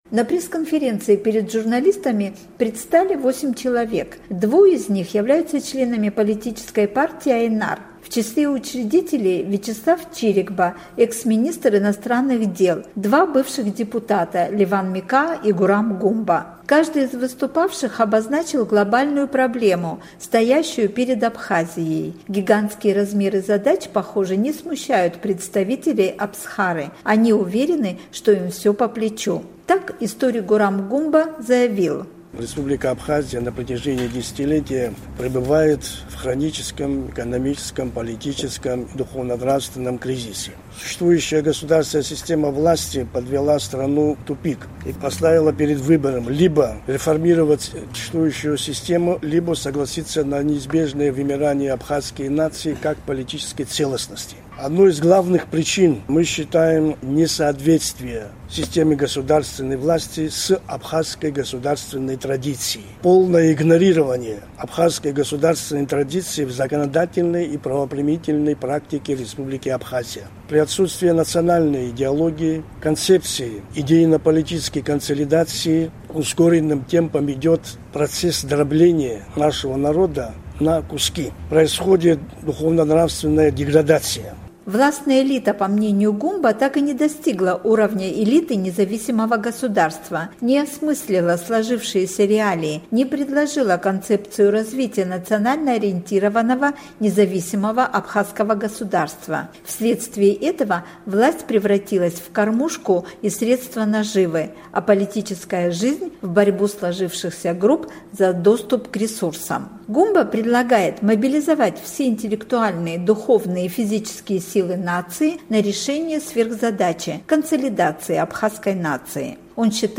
На пресс-конференции перед журналистами предстали восемь человек.